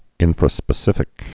(ĭnfrə-spĭ-sĭfĭk)